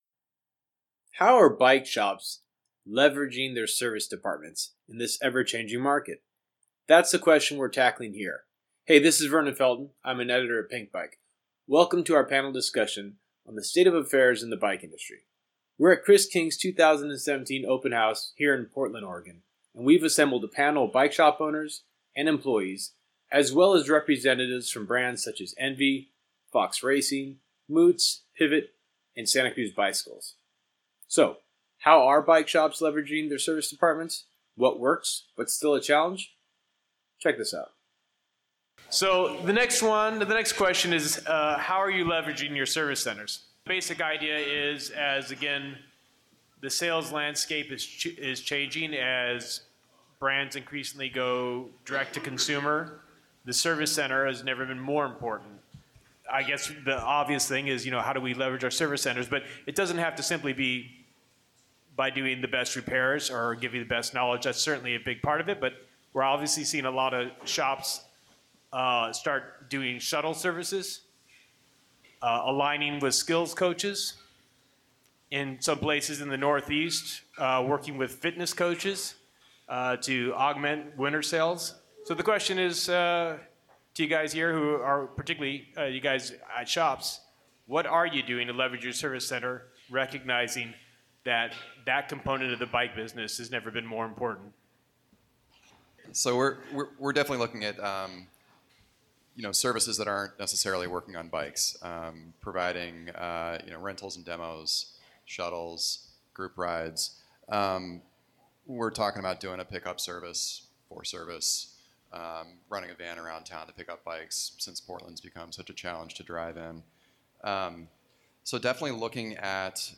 Leading into our 2017 Open House we decided to create an Industry Summit, moderated by Pinkbike, a platform for shops and brands to come together and discuss what we can do to best support consumers and the cycling industry given the current surge of change in the industry.